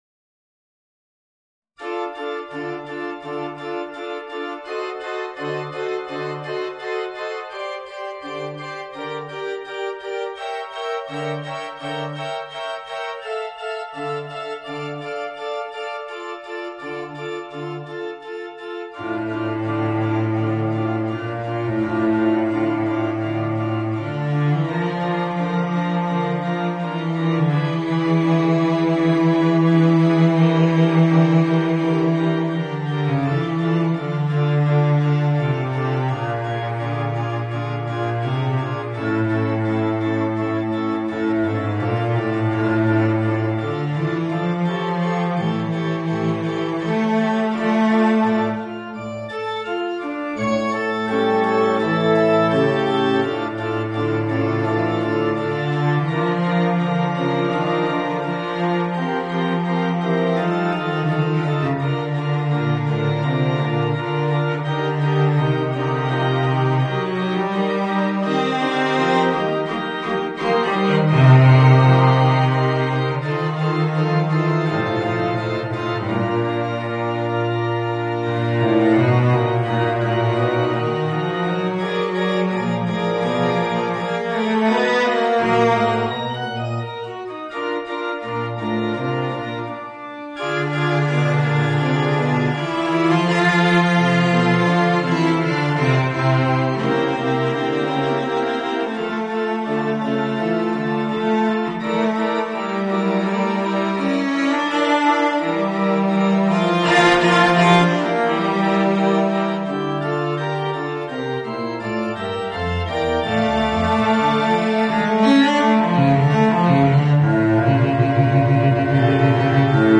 Voicing: Violoncello and Organ